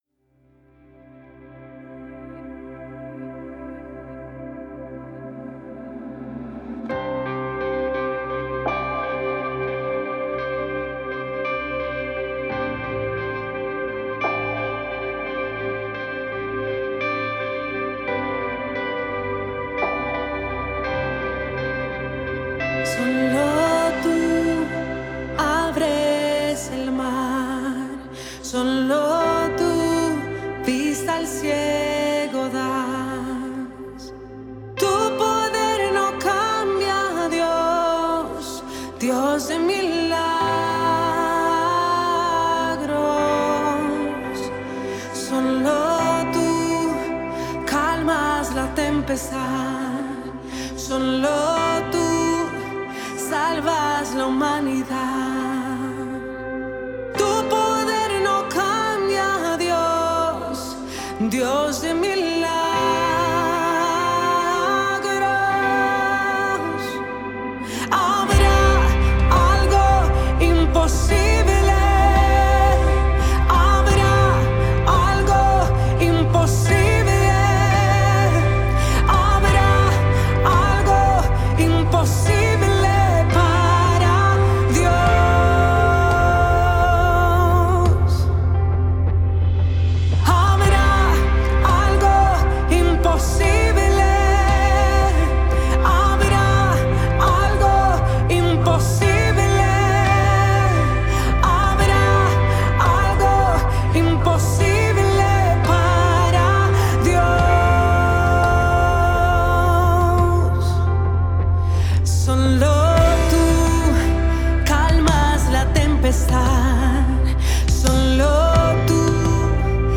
balada